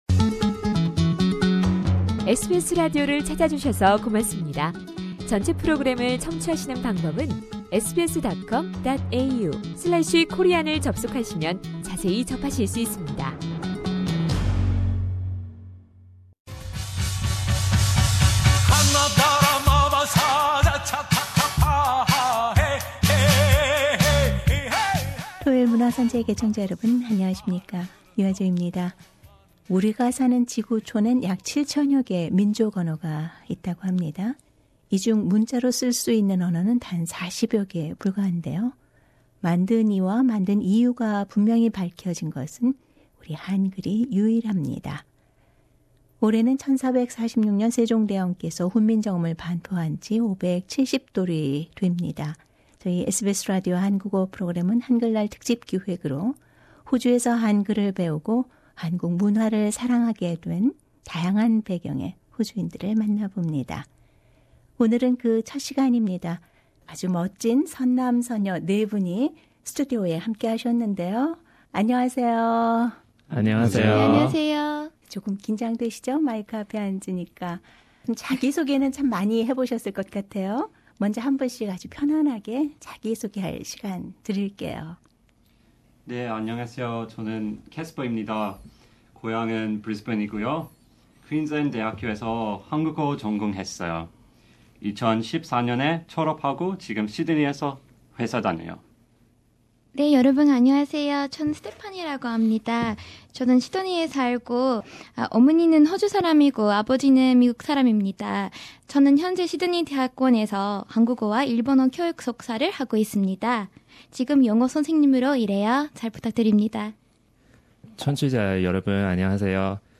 [한글날 특집 대담] “사랑해요, 한글”